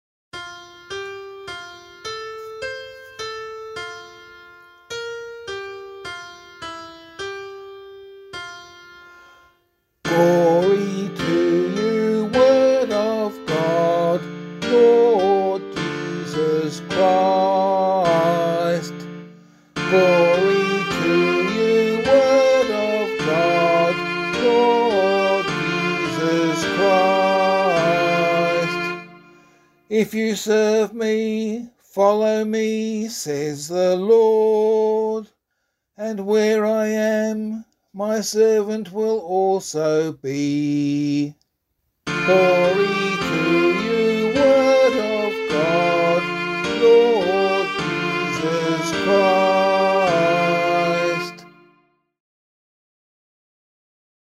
Gospelcclamation for Australian Catholic liturgy.